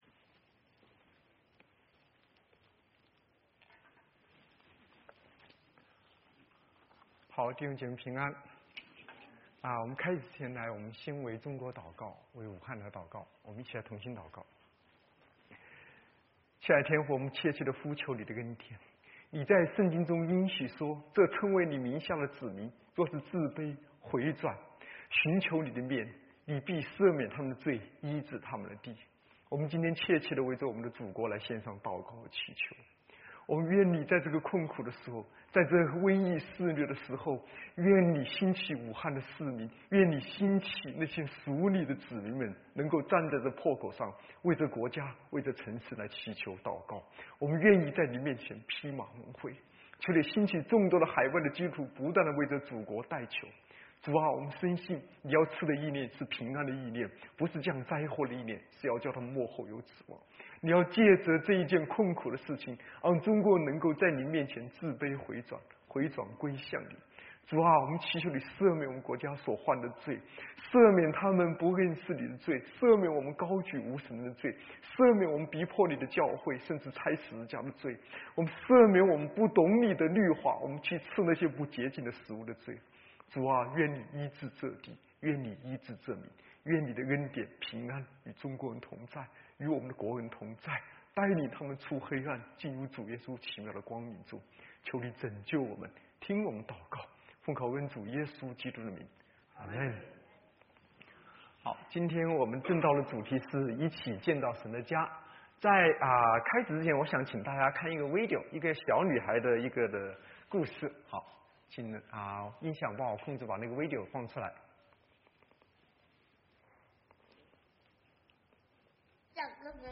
Sermons | South Gate Alliance Church | Mandarin (國語)